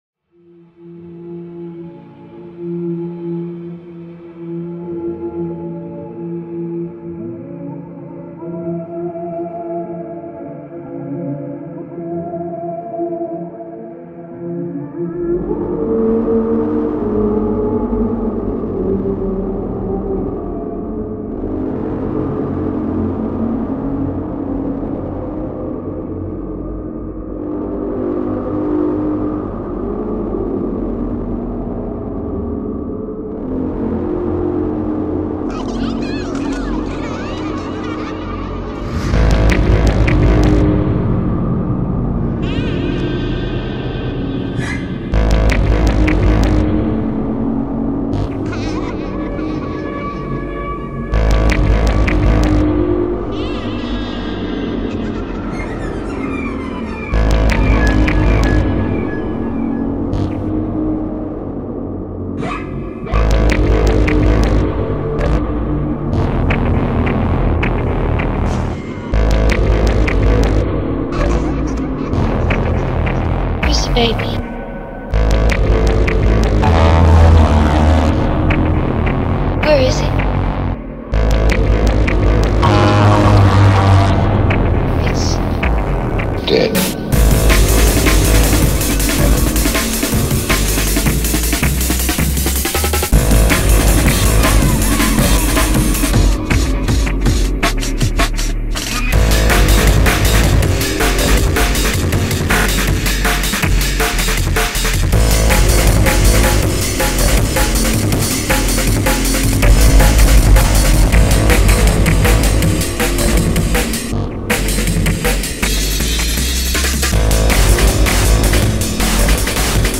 Breakcore = Creepy